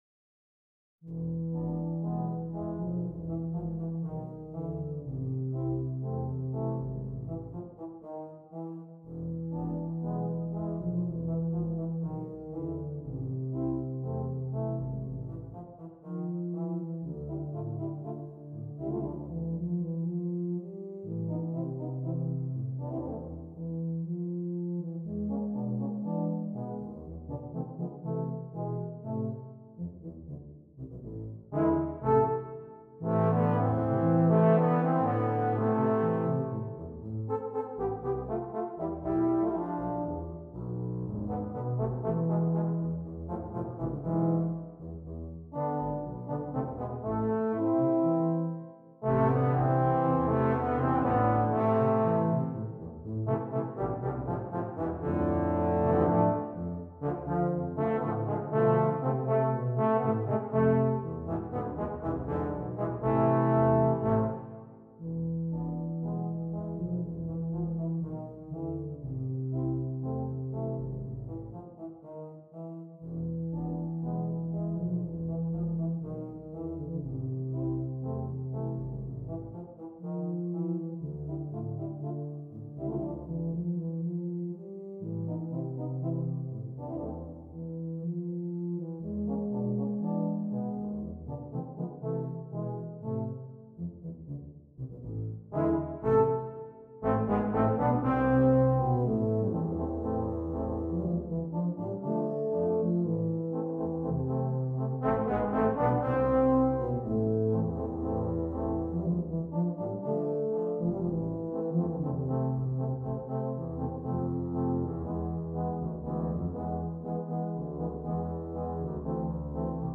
2 Euphoniums, 2 Tubas